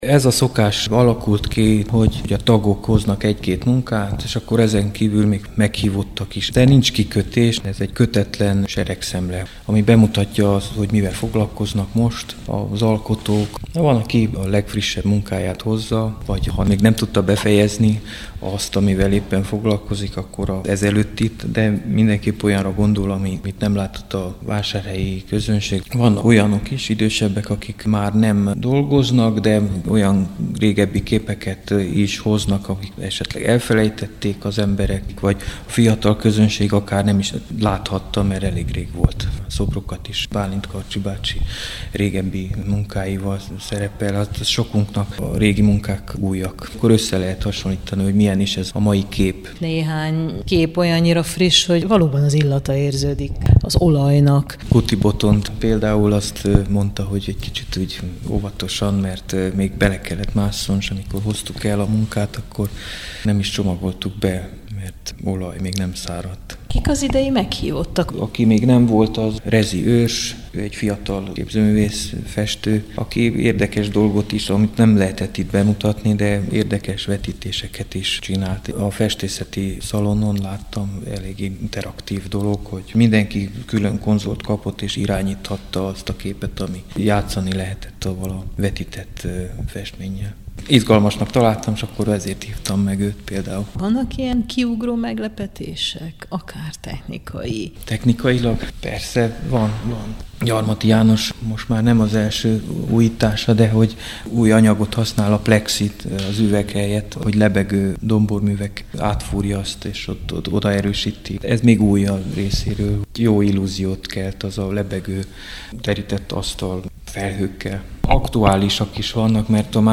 beszélgetünk a kiállításról